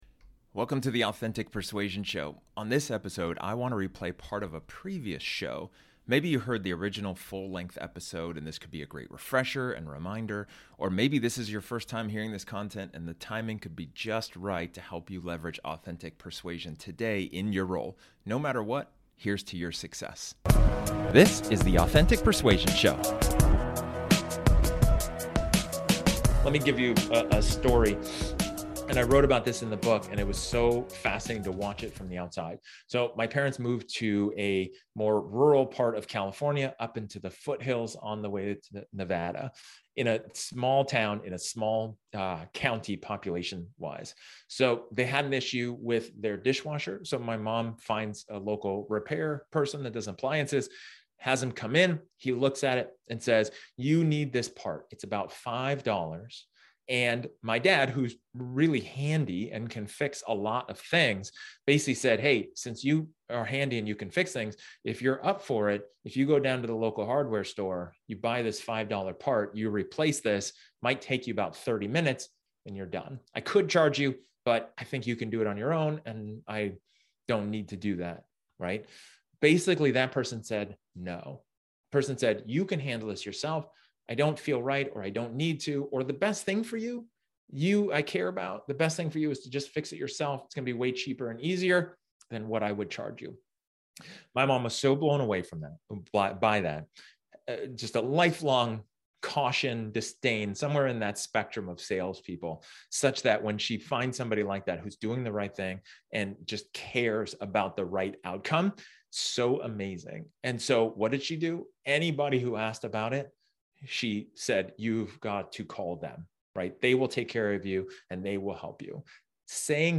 This episode is an excerpt from one of my training sessions where I talk about empathizing for success.